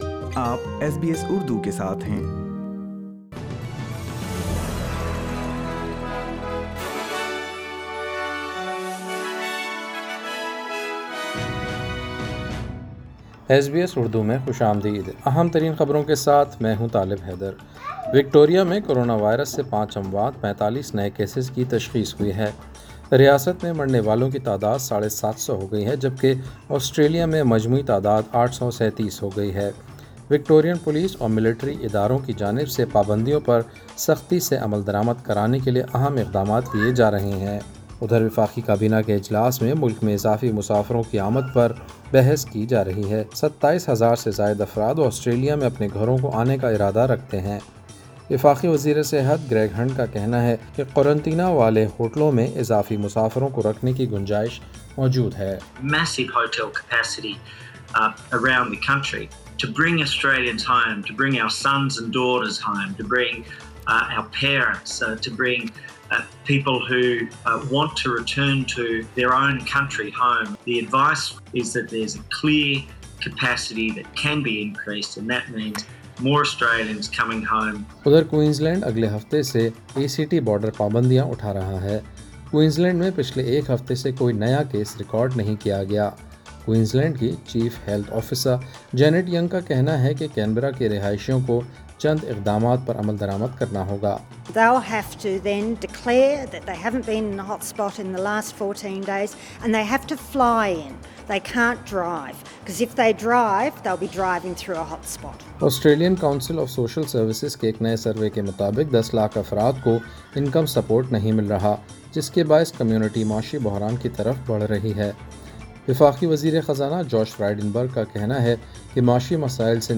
ایس بی ایس اردو خبریں 18 ستمبر 2020